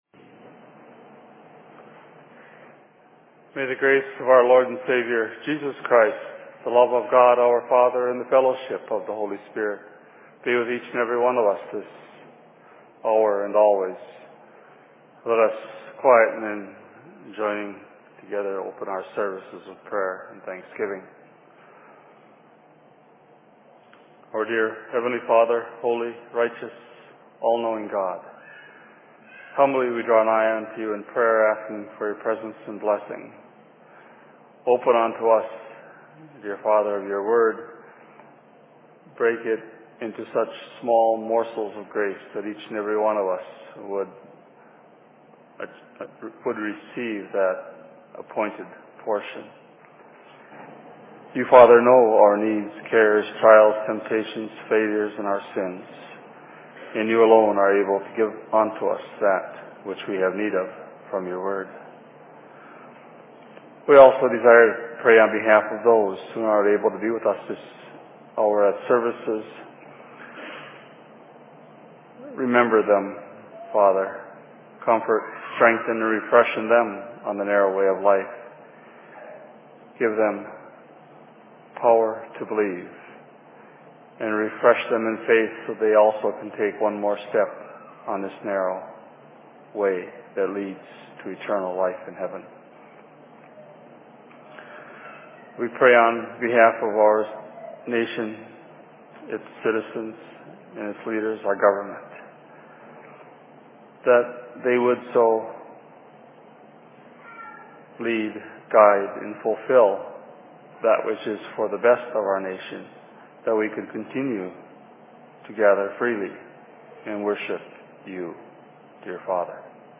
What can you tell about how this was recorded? Sermon in Minneapolis 01.06.2008 Location: LLC Minneapolis